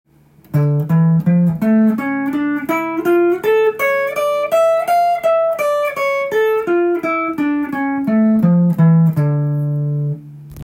６弦ルート